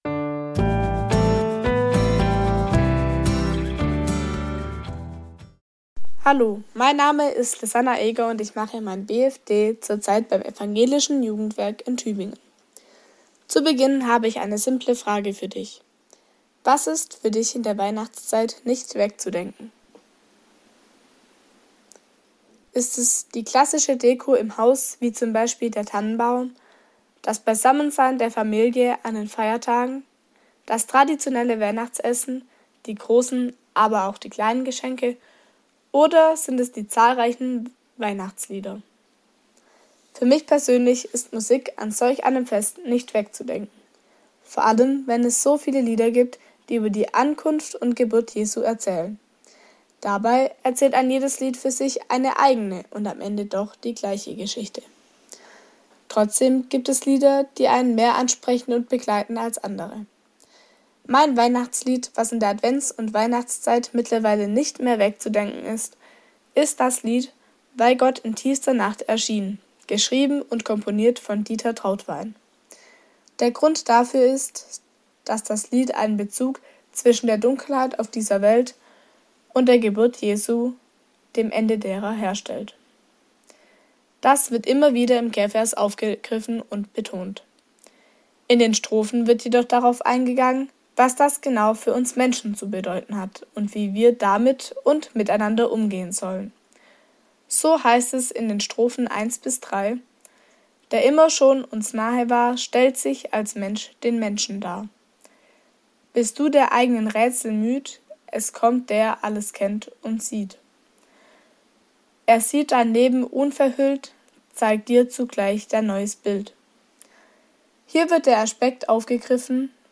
Andacht zur Weihnachtswoche